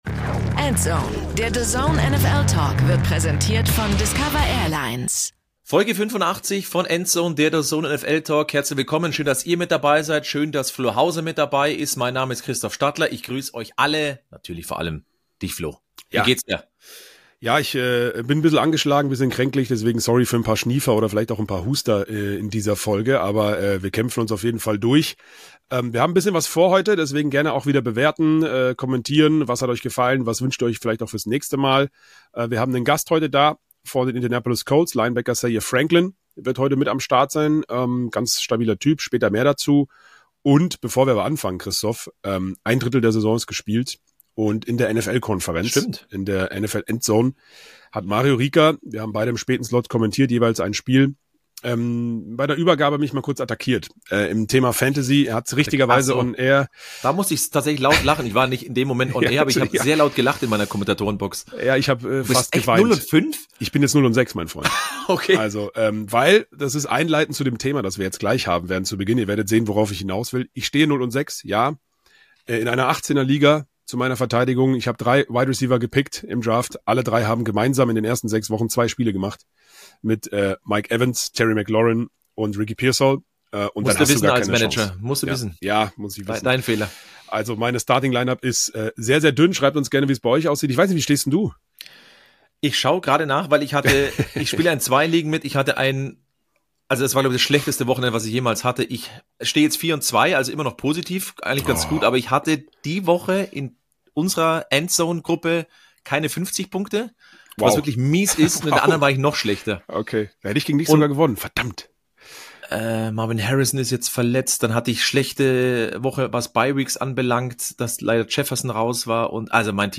Liebe Grüße an Zaire Franklin und danke dir fürs Interview.